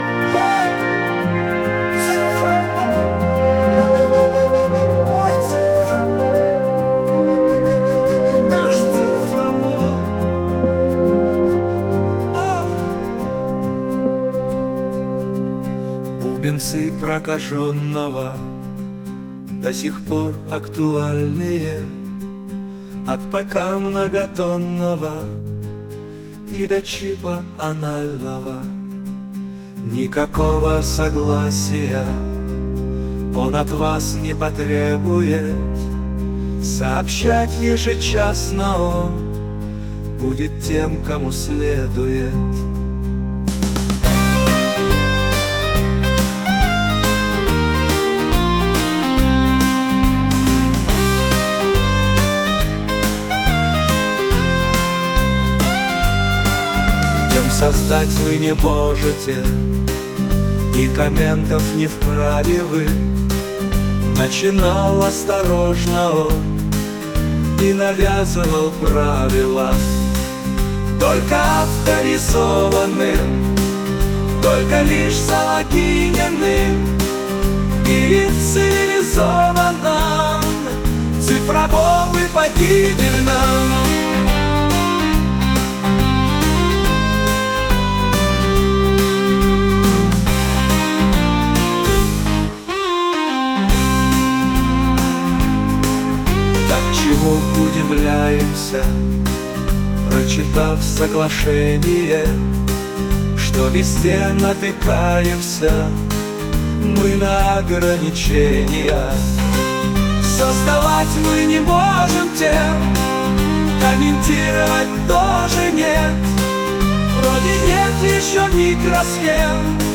• Жанр: Фолк